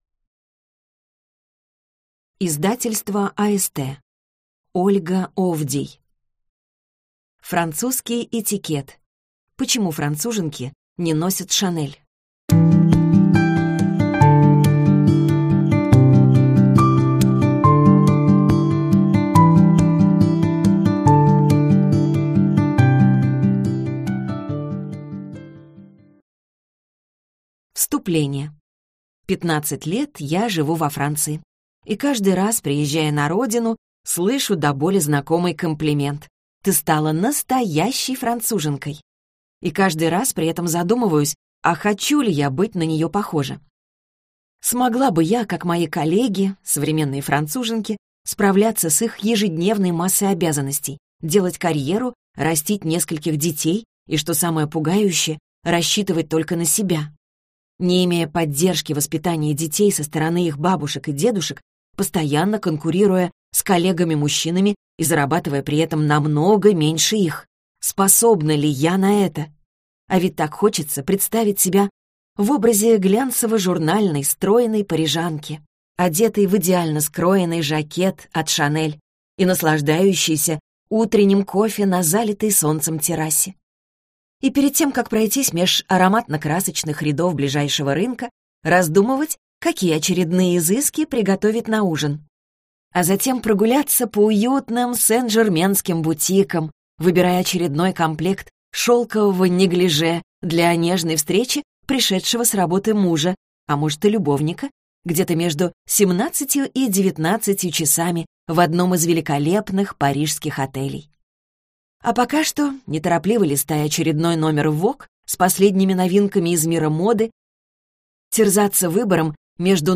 Аудиокнига Французский этикет. Почему француженки не носят Шанель | Библиотека аудиокниг
Прослушать и бесплатно скачать фрагмент аудиокниги